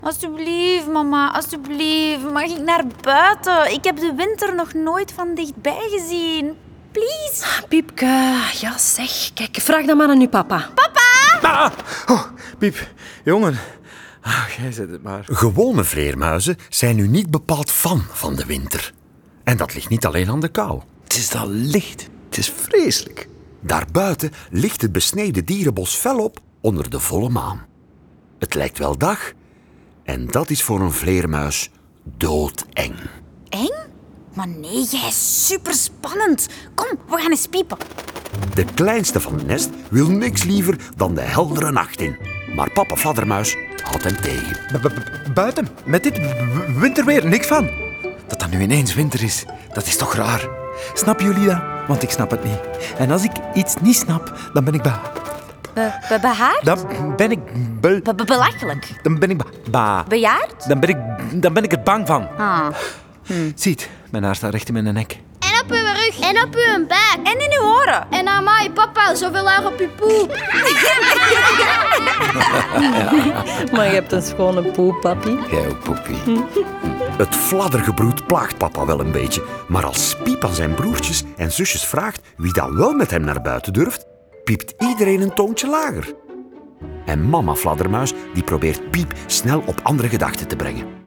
Met de stemmen van Dominique Van Malder, Evelien Bosmans, Liesa Van der Aa, Wim Willaert, Noémie Wolfs, Tom Vermeir en Lien De Graeve.
De rollen worden ingesproken door de béste acteurs en in bijhorend prentenboek staat ook een voorleestekst.